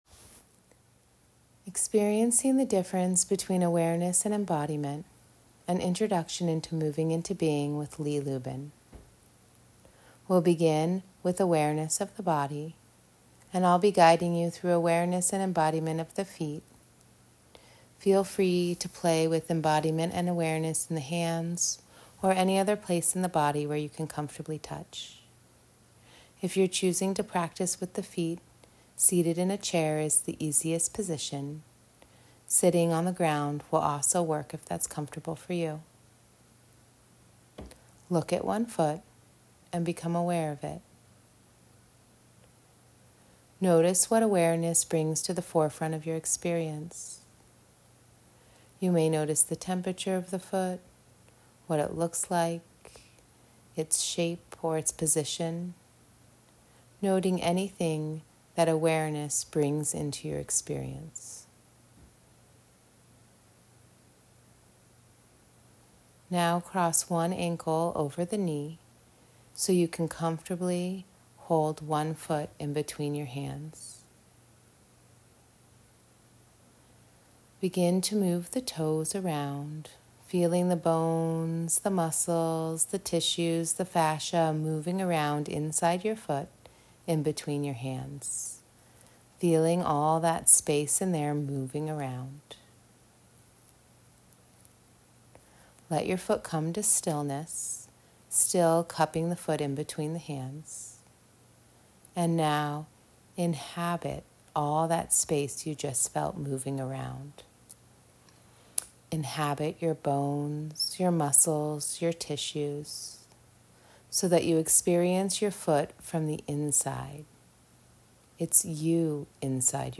Short guided recording to experience the difference between awareness and embodiment.